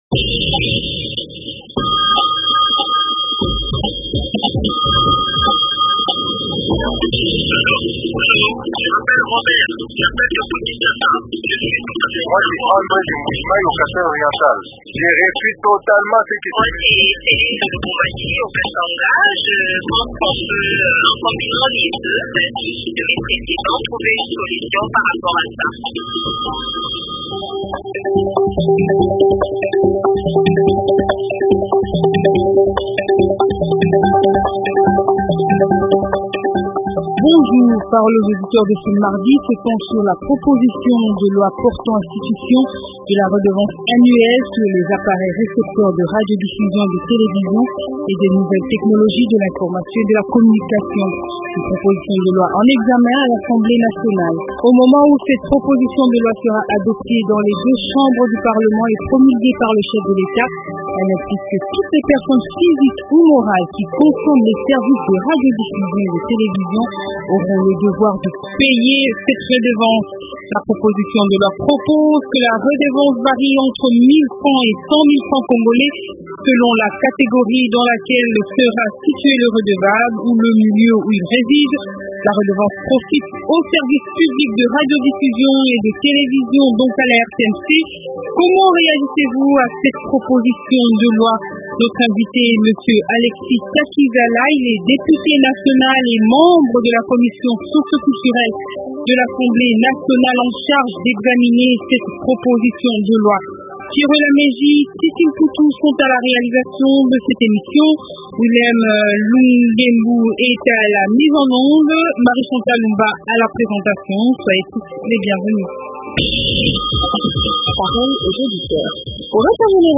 Invité : Alexis Takizala, député national et membre de la commission socioculturelle de l’Assemblée nationale en charge d’examiner cette proposition de loi.